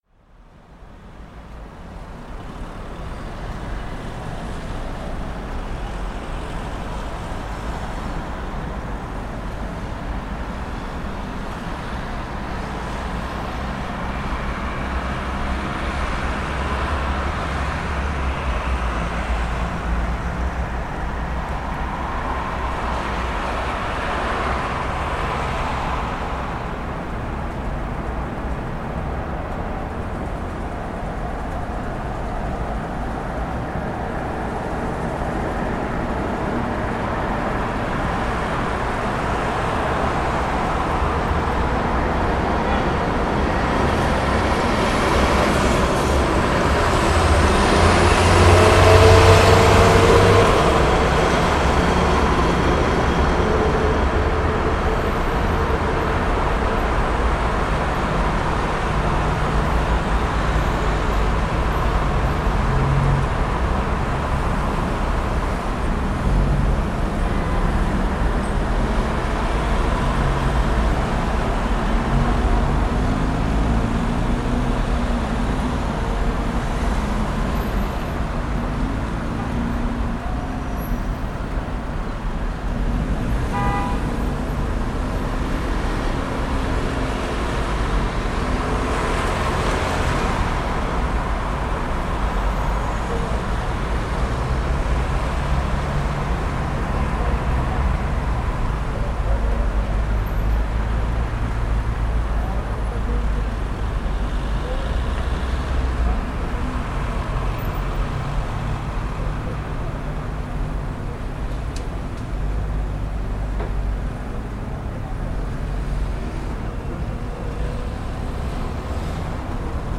دانلود صدای بزرگراه از ساعد نیوز با لینک مستقیم و کیفیت بالا
جلوه های صوتی
برچسب: دانلود آهنگ های افکت صوتی طبیعت و محیط